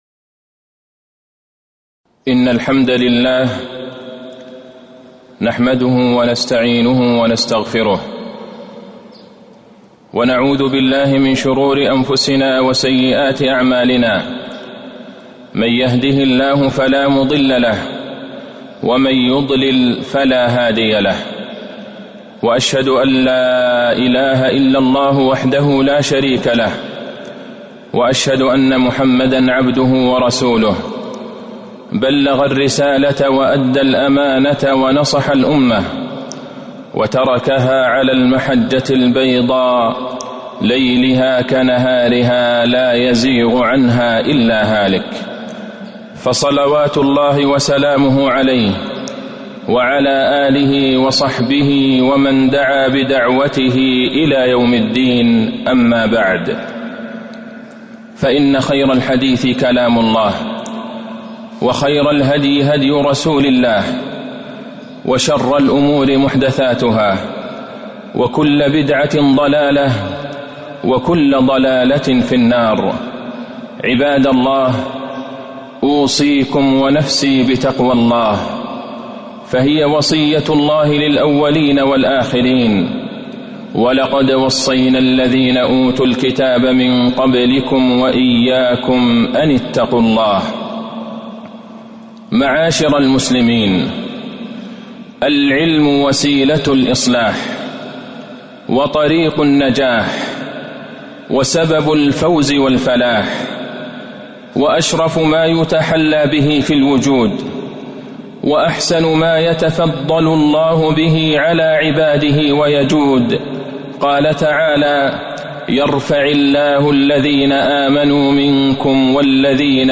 تاريخ النشر ٢٧ ربيع الأول ١٤٤٢ هـ المكان: المسجد النبوي الشيخ: فضيلة الشيخ د. عبدالله بن عبدالرحمن البعيجان فضيلة الشيخ د. عبدالله بن عبدالرحمن البعيجان الحث على طلب العلم